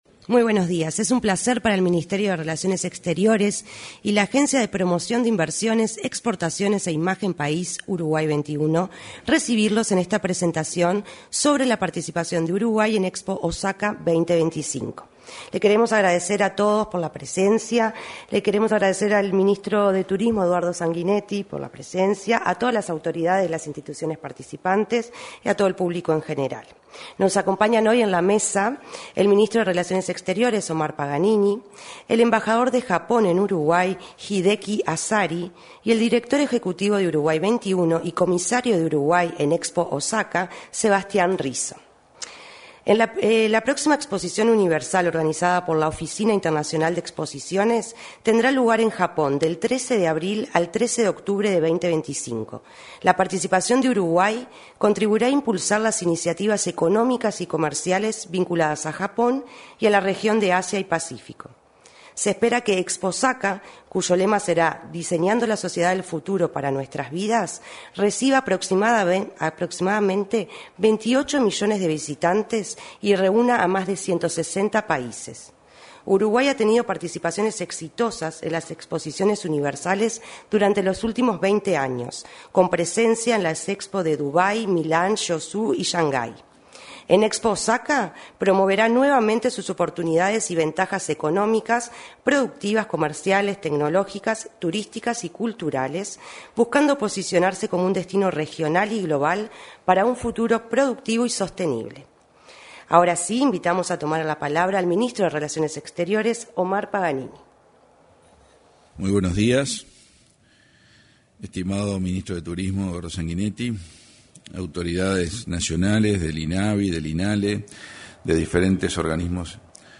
En la oportunidad, se expresaron el ministro de Relaciones Exteriores, Omar Paganini; el embajador de Japón en Uruguay, Hideki Asari, y el director ejecutivo de Uruguay XXI, Sebastián Risso.